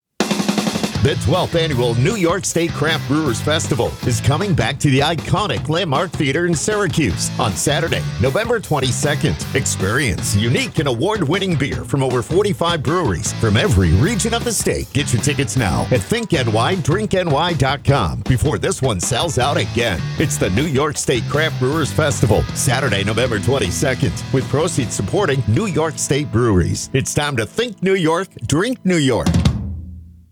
Middle Aged
Senior